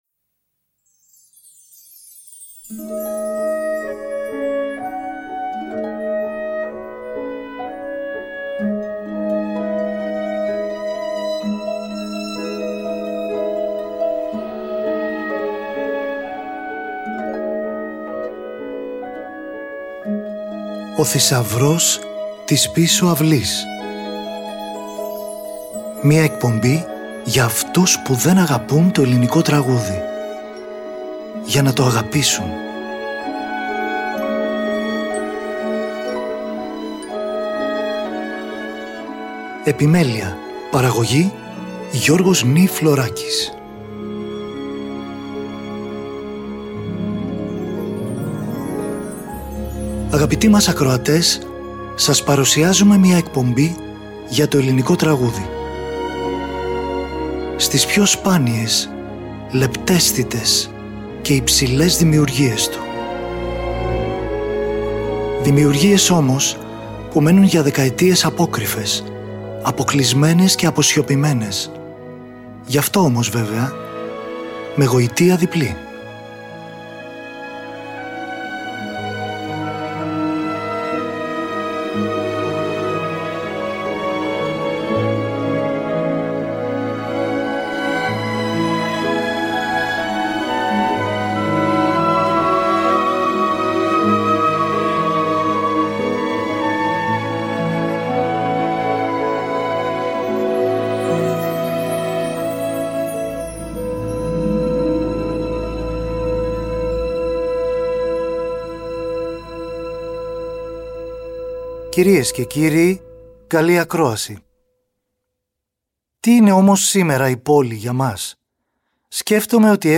Ελληνικη Μουσικη στο Τριτο